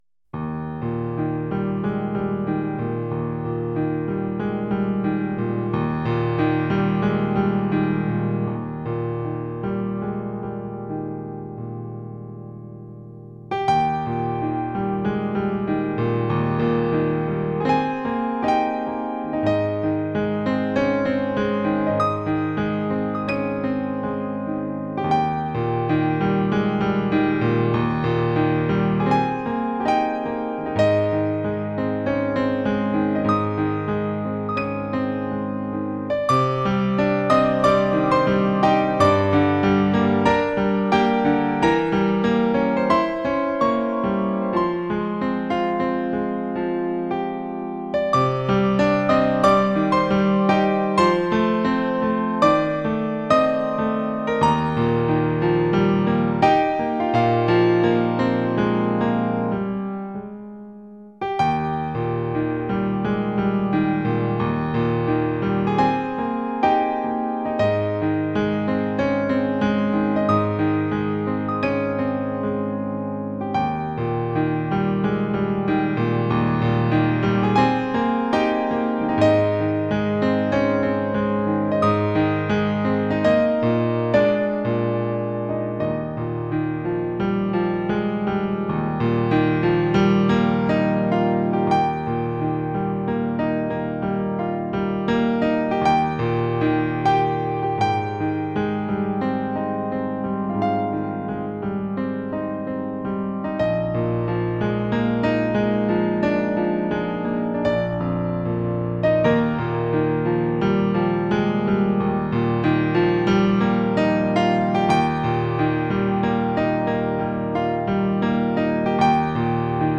instrumentala